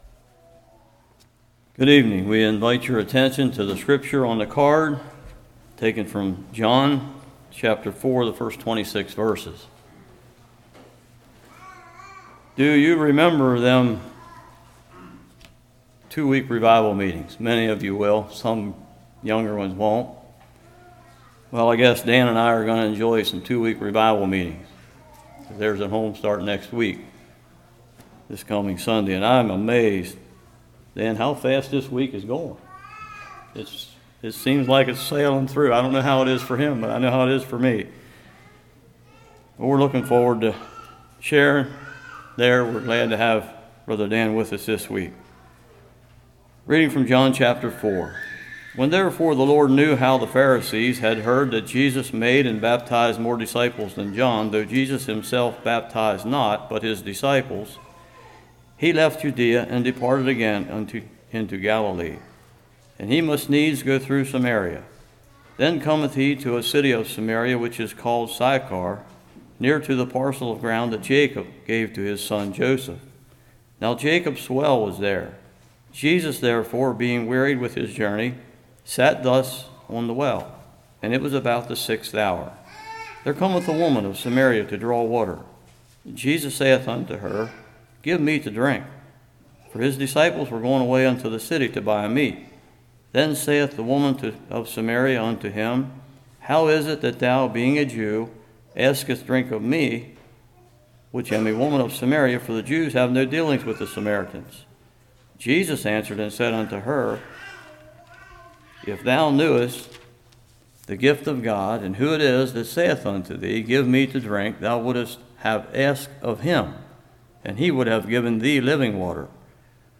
John 4:1-26 Service Type: Revival Jesus produces a thirst in the woman.